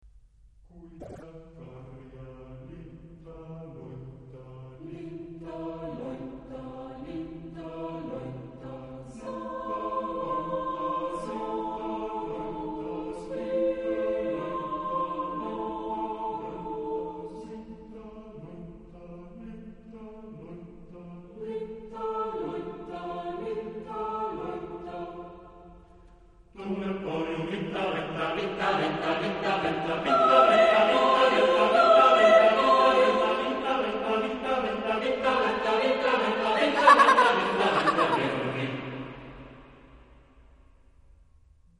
Genre-Style-Form: Popular ; Children ; Partsong
Mood of the piece: joyous ; lively
Type of Choir: SATB  (4 children voices )
Tonality: C major